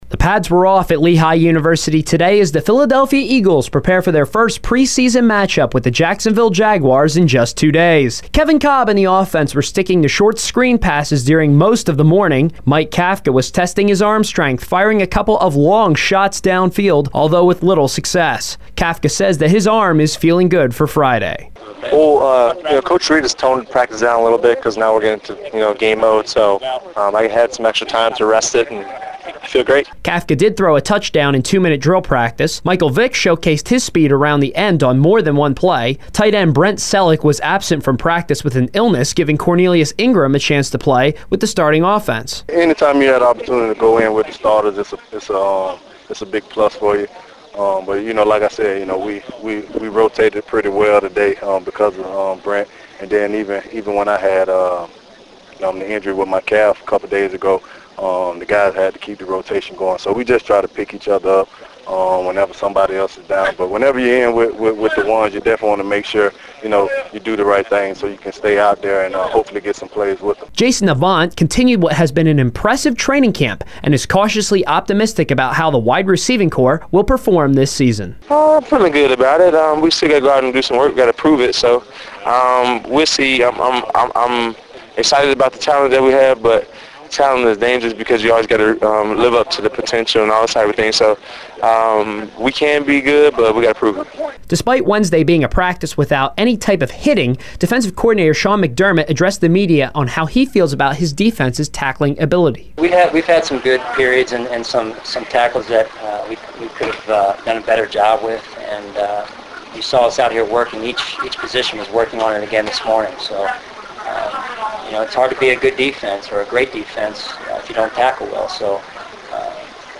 The reports included audio I gathered from interviews with both players and coaches.